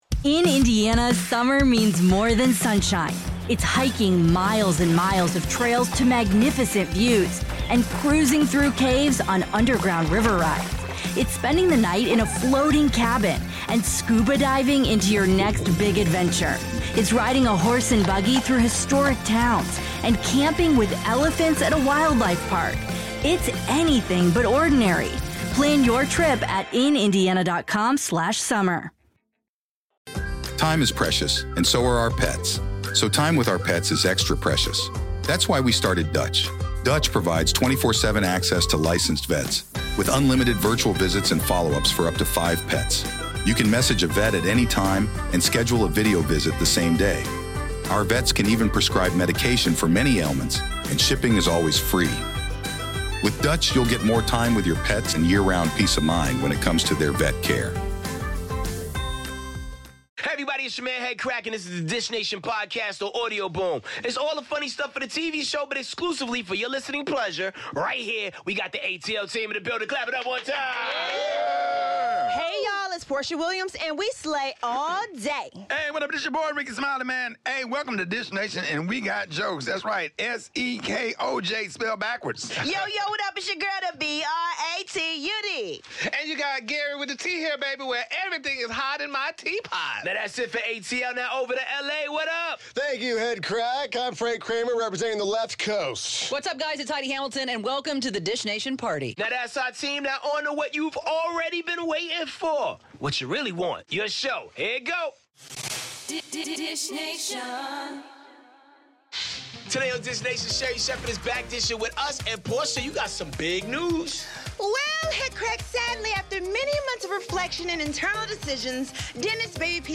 Kate Beckinsale hangs out with Machine Gun Kelly, Prince Harry & Meghan Markle make their royal exit and Jillian Michaels comes for Lizzo. Sherri Shepherd is BACK in studio with us so tune in to today's Dish Nation!